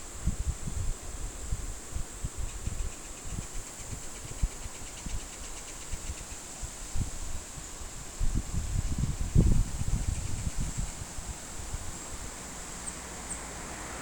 Ringed Kingfisher (Megaceryle torquata)
Location or protected area: Monteros
Condition: Wild
Certainty: Recorded vocal